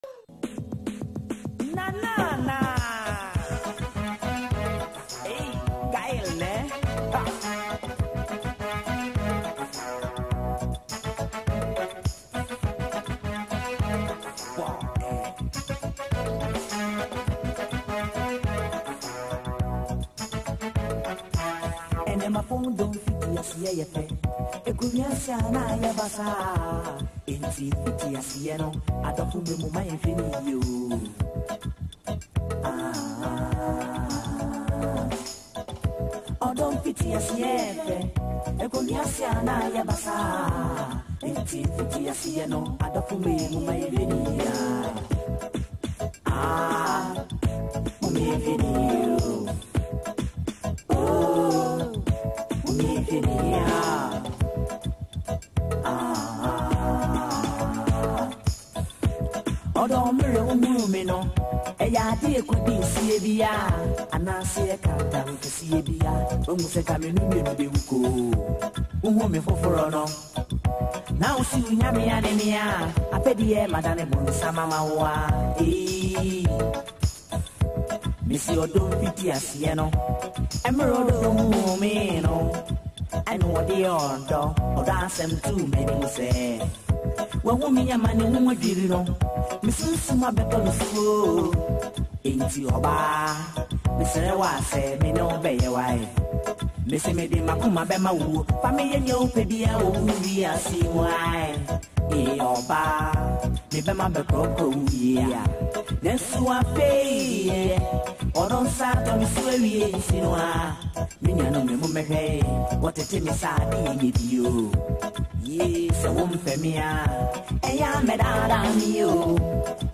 highlife
Known for his smooth voice and deep emotional lyrics